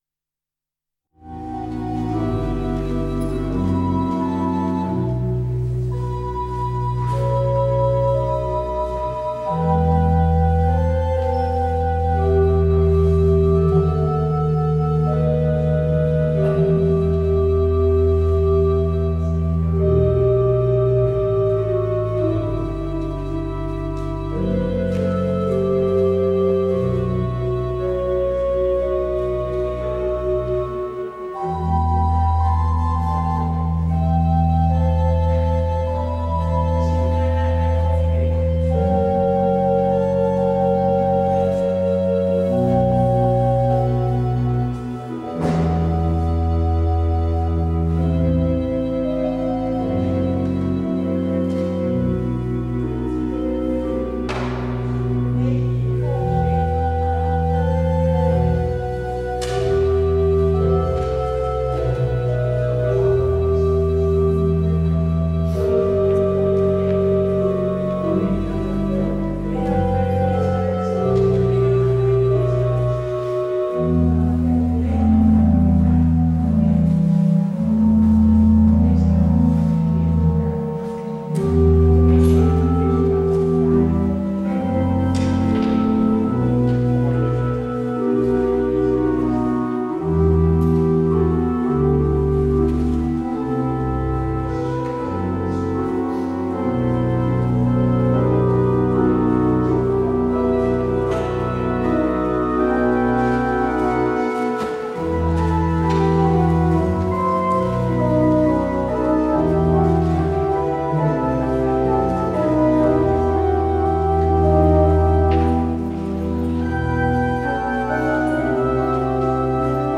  Beluister deze kerkdienst hier: Alle-Dag-Kerk 18 maart 2026 Alle-Dag-Kerk https